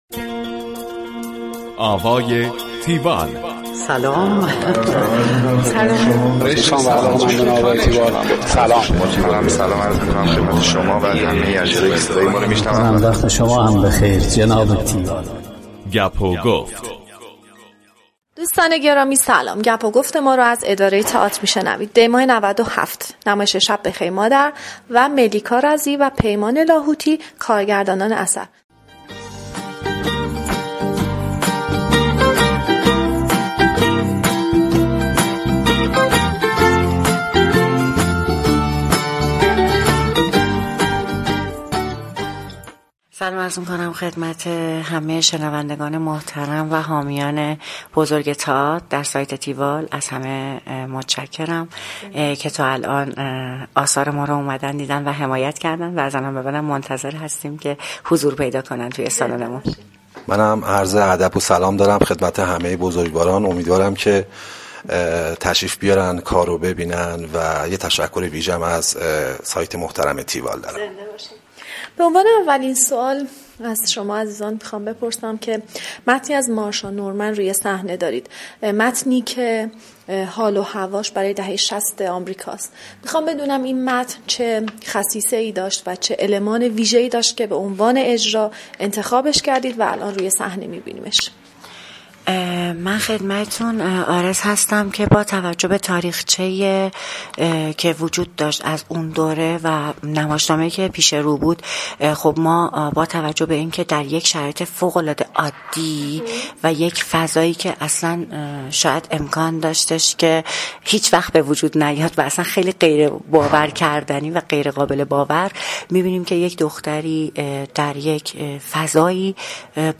tiwall-interview-shabbekheirmadar.mp3